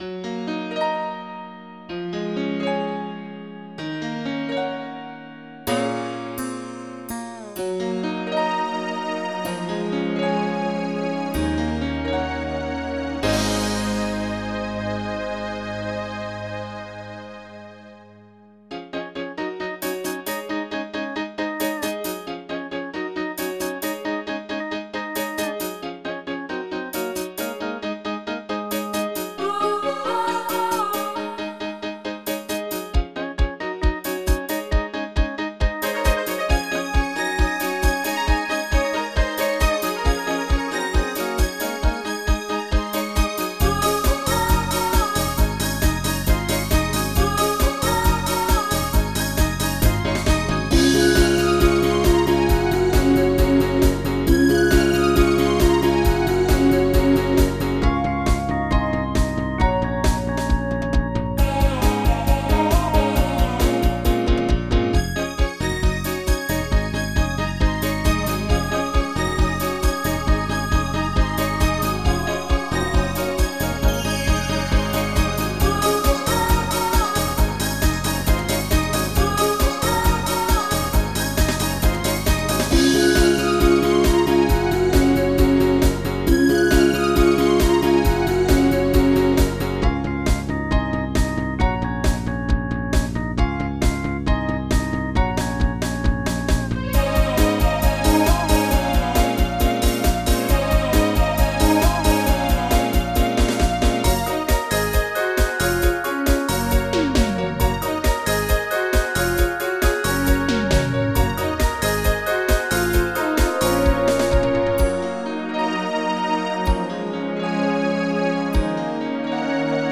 Pop
MIDI Music File
General MIDI